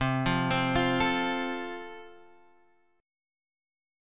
C 6th Suspended 4th